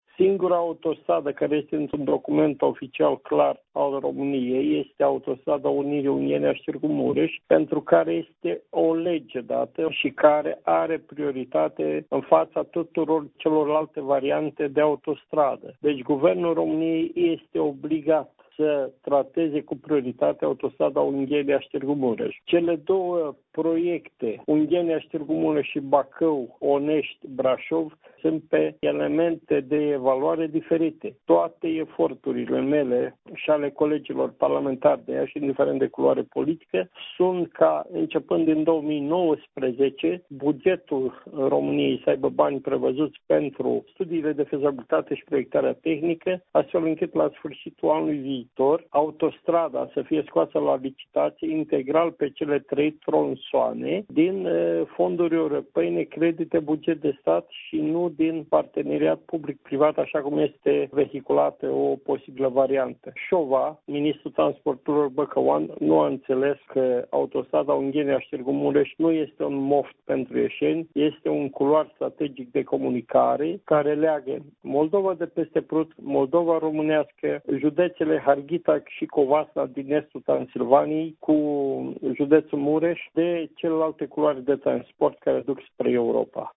Deputatul PMP de Iaşi, Petru Movilă, iniţiatorul legii privind construcţia Autostrăzii 8, Ungheni-Iaşi-Tg. Mureş, a declarat, pentru postul nostru de radio, că Executivul este obligat să ţină cont de actul normativ, şi să acorde prioritate acestui proiect important de infrastructură care ar urma să lege Moldova de Transilvania.